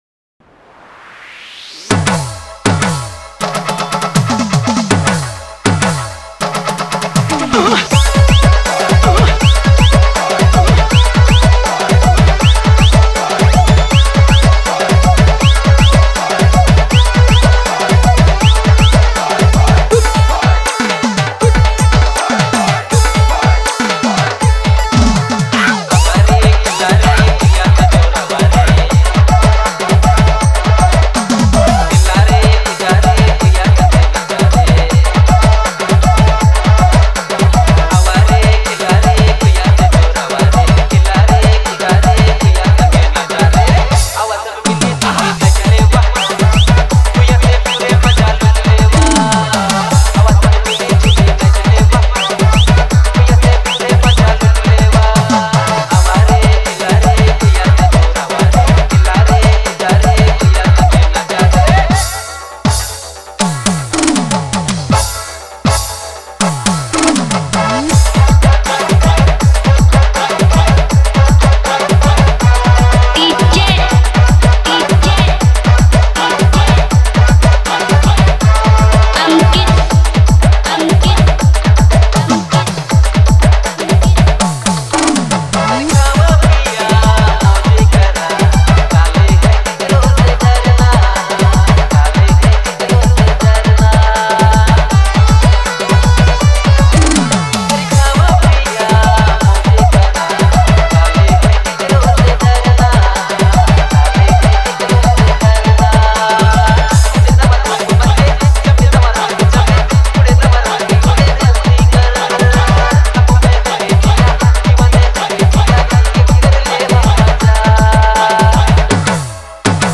fun and energetic Nagpuri remix